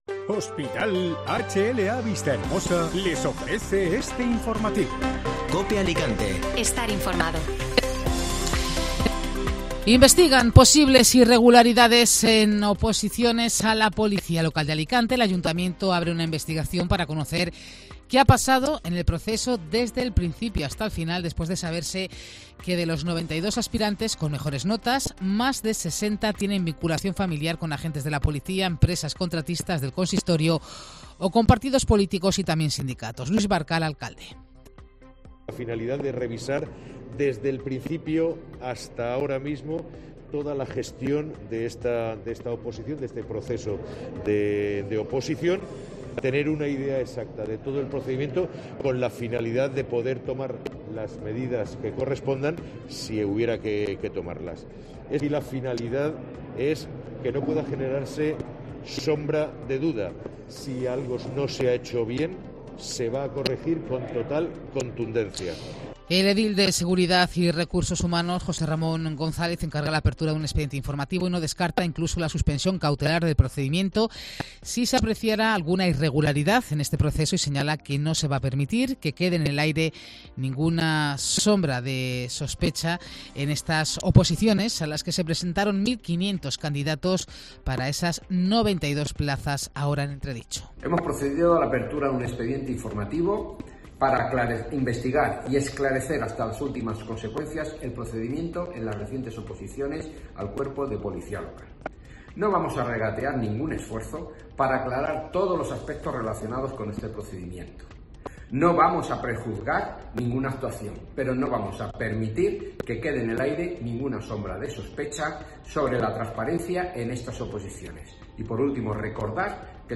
Informativo Mediodía COPE (Lunes 28 de marzo)